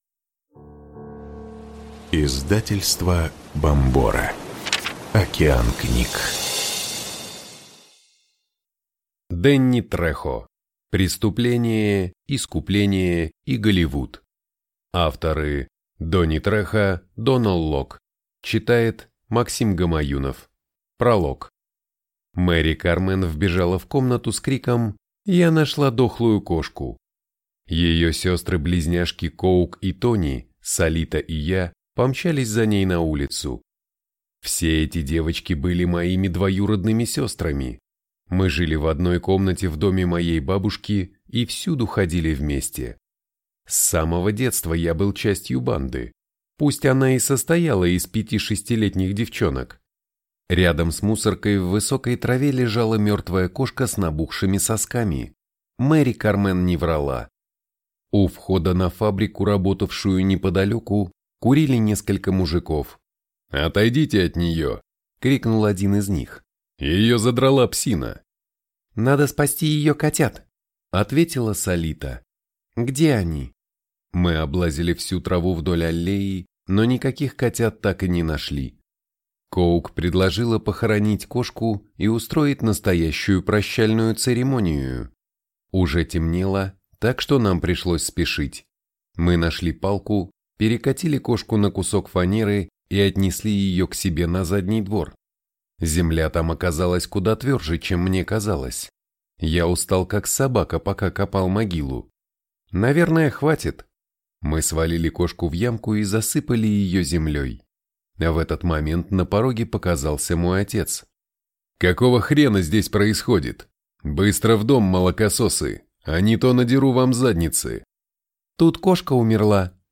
Аудиокнига Дэнни Трехо. Преступление, искупление и Голливуд | Библиотека аудиокниг